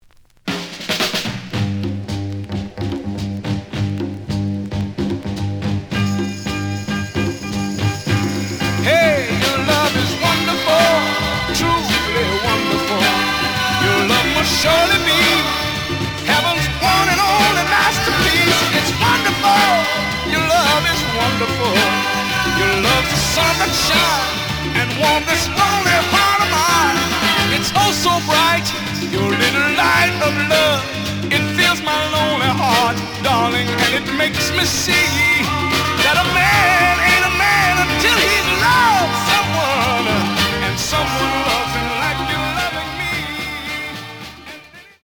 The audio sample is recorded from the actual item.
●Genre: Soul, 60's Soul
Some damage on both side labels. Plays good.)